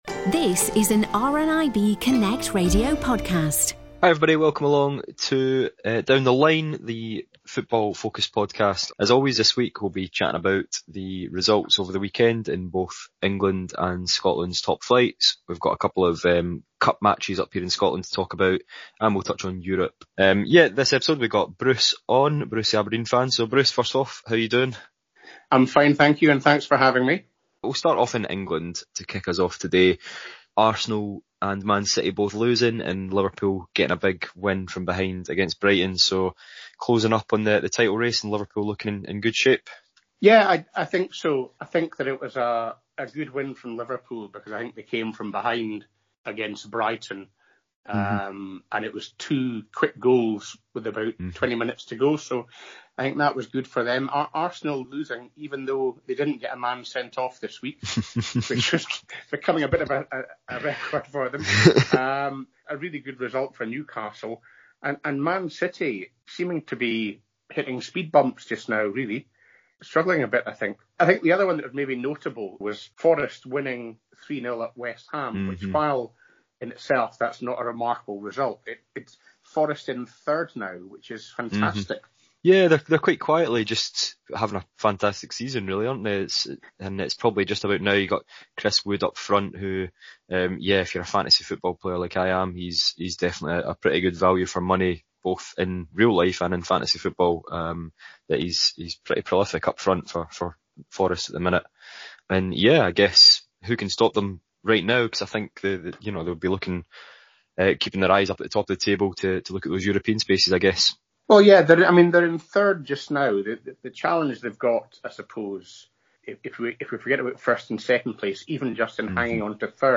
Football-loving members of RNIB ‘Community Connections’ telephone groups get together each week to talk about the latest going on in leagues across Scotland and England. This time, looking back at a busy week of domestic and European fixtures.